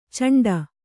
♪ caṇḍa